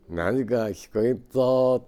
Aizu Dialect Database
Type: Statement
Final intonation: Falling
Location: Showamura/昭和村
Sex: Male